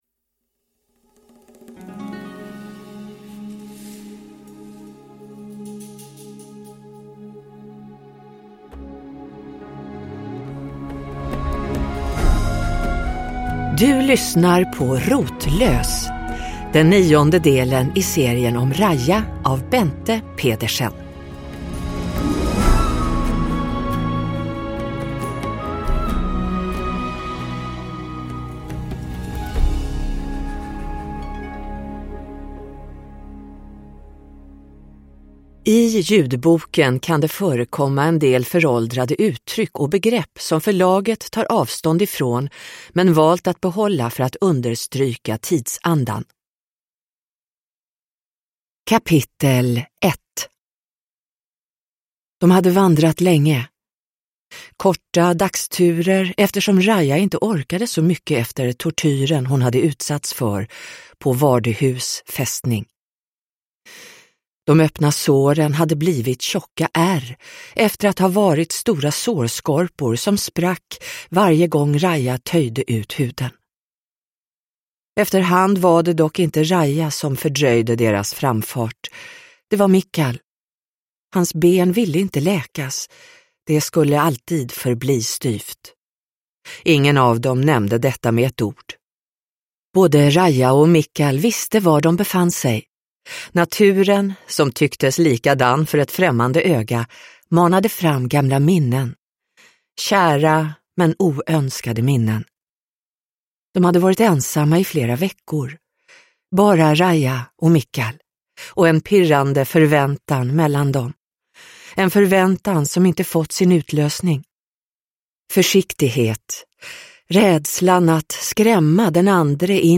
Rotlös – Ljudbok